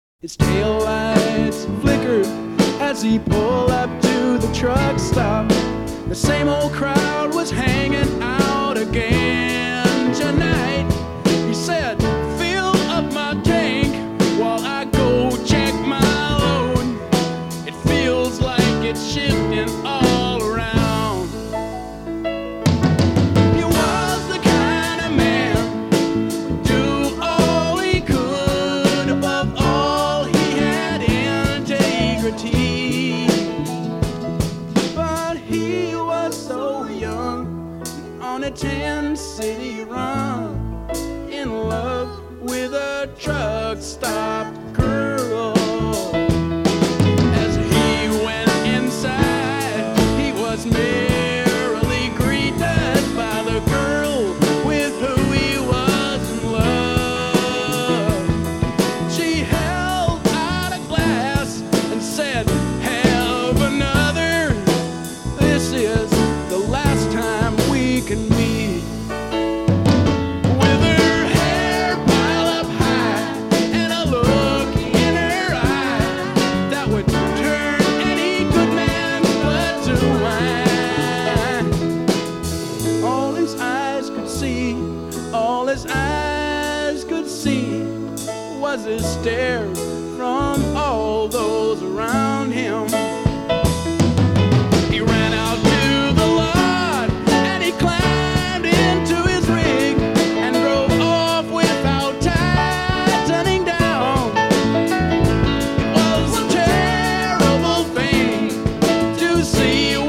Allmusic.comAMG）五星滿點推薦，藍味十足的南方搖滾經典之作！